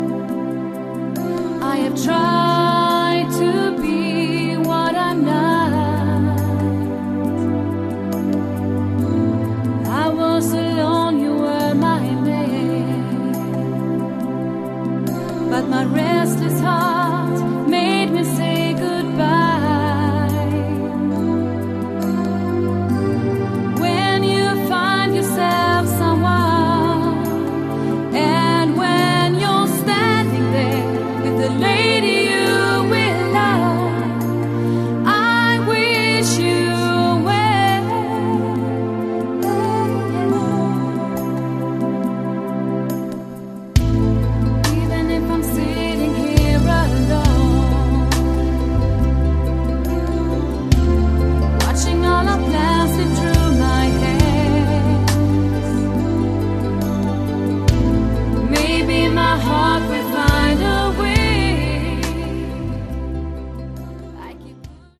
Category: AOR/Melodic Rock
Vocals
Guitars
Bass
Drums, Keyboards